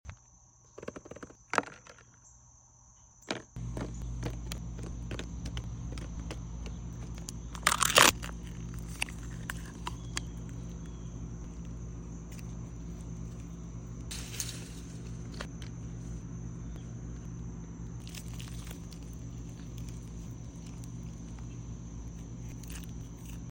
Nacho Themed Slime Asmr Sound Effects Free Download